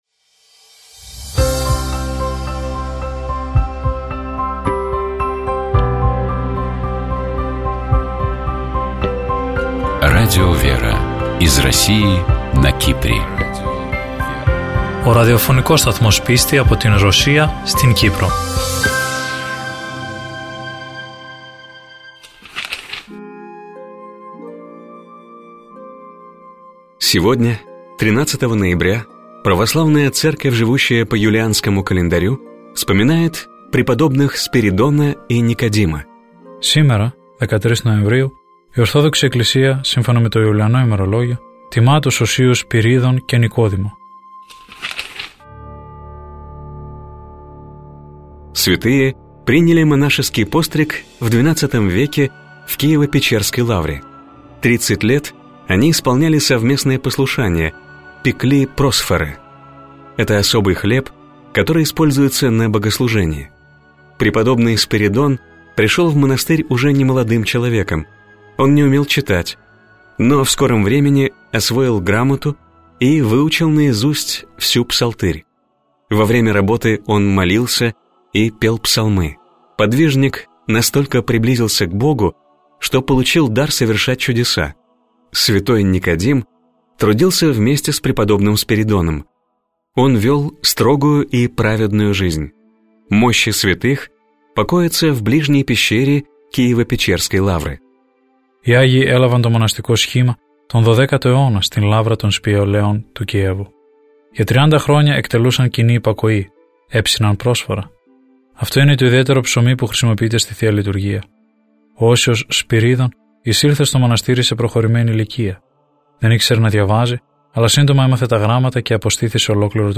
Давайте поговорим о степенном антифоне 6-го гласа, который является ответом и своего рода развитием темы 122-го псалма пророка Давида. Поразмышляем над текстом и послушаем этот антифон в исполнении сестёр Орского Иверского женского монасты